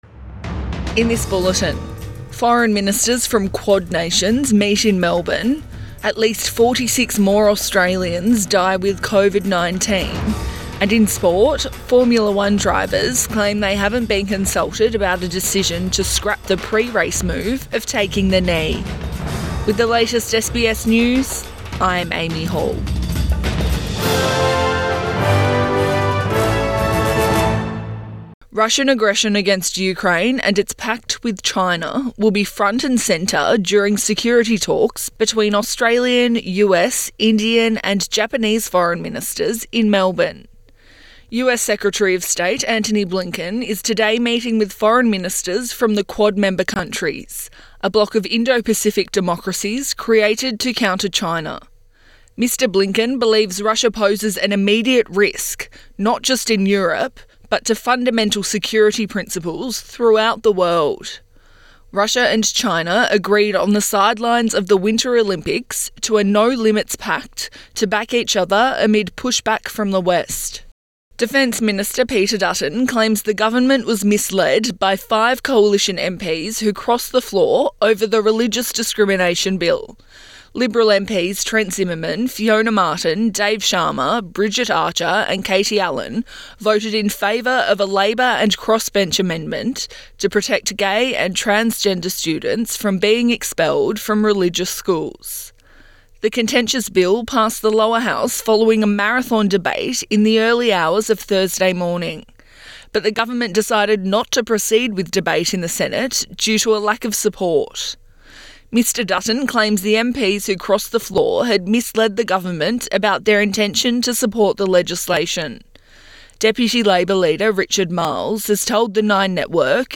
Midday bulletin 11 February 2022